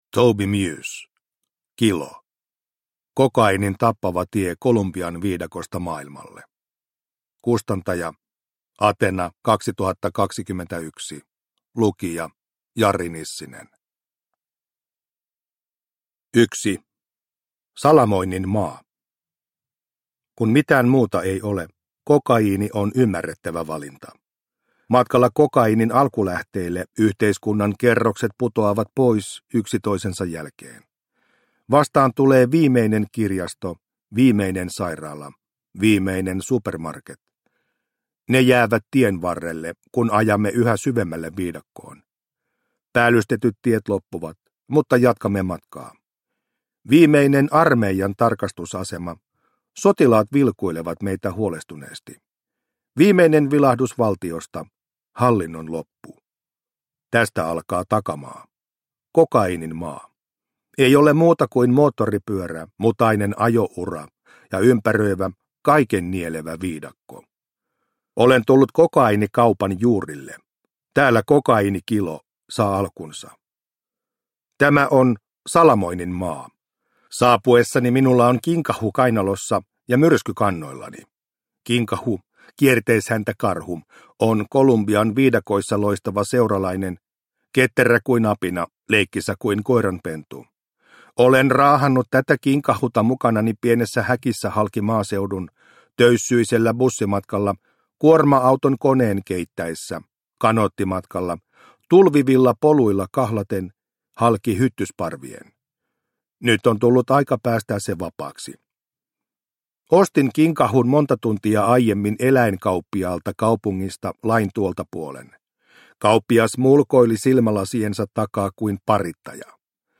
Kilo – Ljudbok – Laddas ner